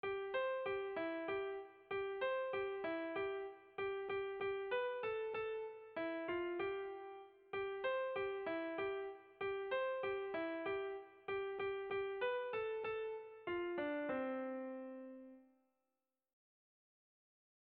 Irrizkoa
Lauko handia (hg) / Bi puntuko handia (ip)
A1A2